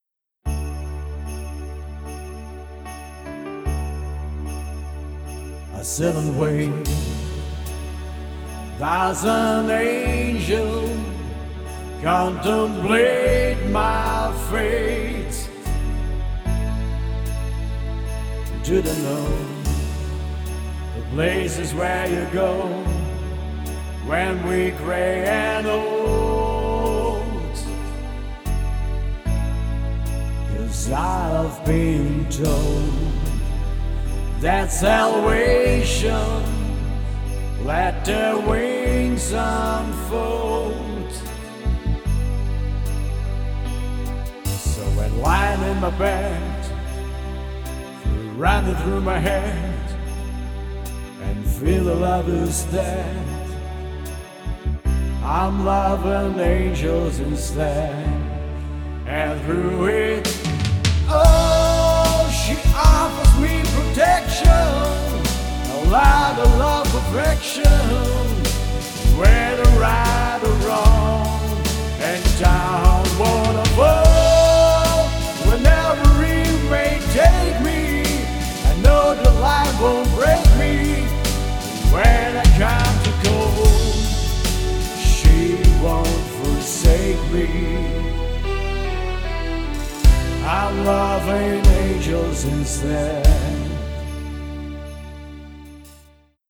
Pop & Rock & Swing